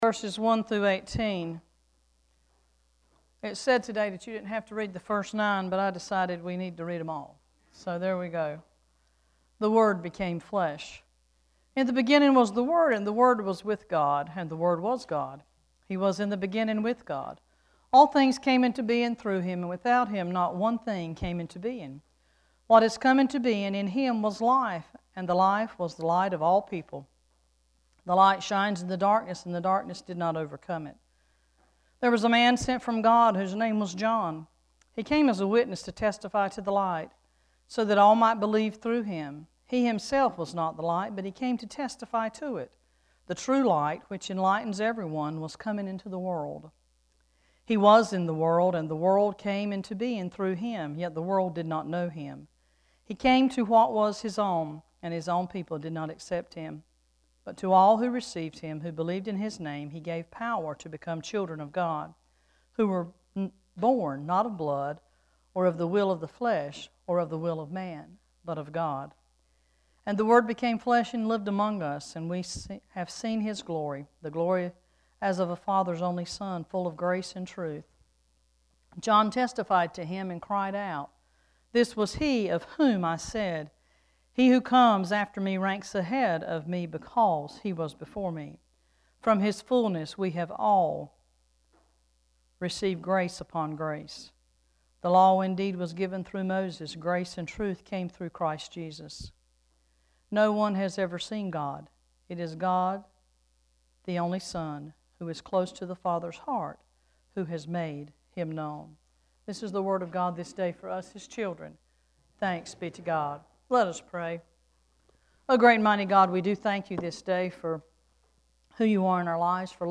Worship Service 1-5-14: Light in the Darkness
1-5-14-scripture.mp3